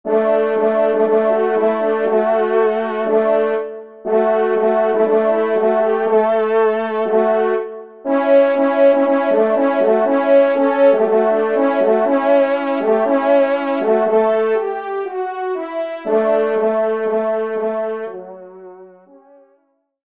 Genre : Fantaisie Liturgique pour quatre trompes
Pupitre 3° Trompe